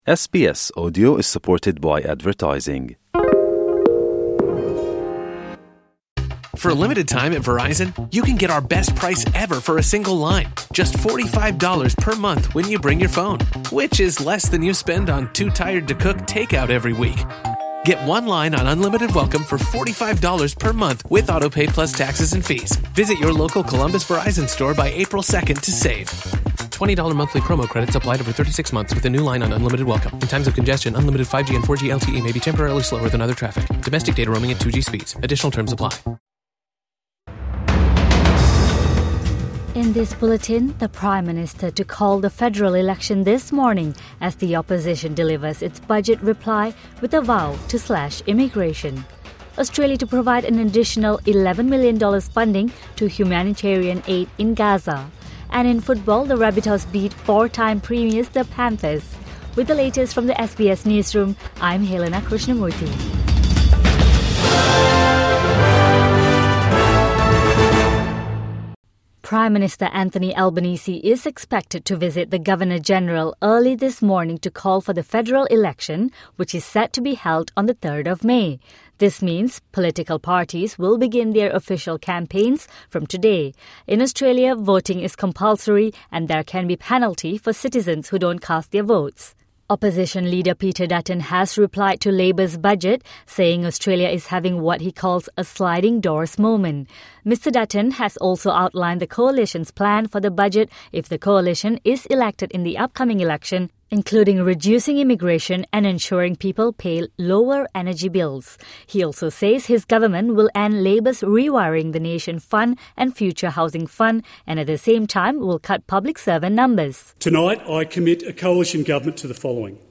A bulletin of the day’s top stories from SBS News. Get a quick rundown of the latest headlines from Australia and the world, with fresh updates each morning, lunchtime and evening.